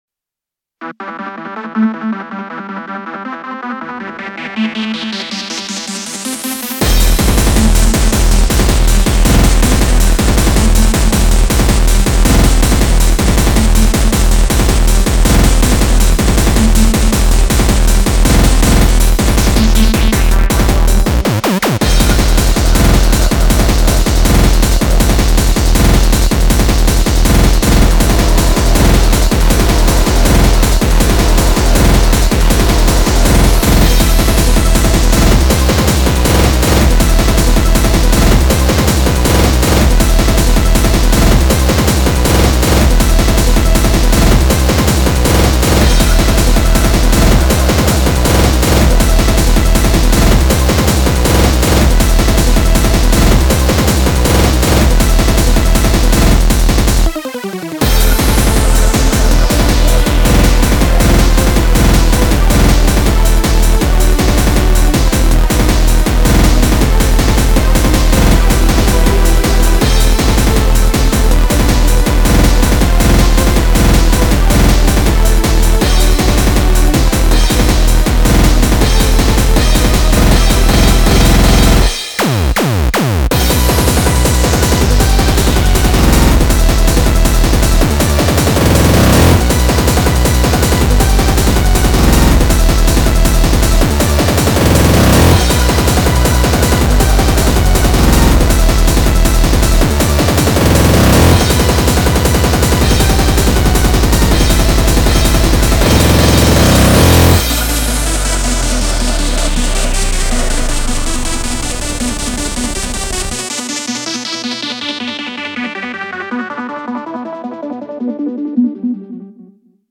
BPM80-320
Genre: Speedcore